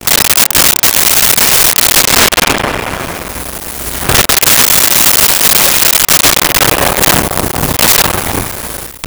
Doors Jail Open Close 02
Doors Jail Open Close 02.wav